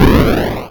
fire_c.wav